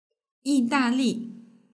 yì dà lì
yidali.mp3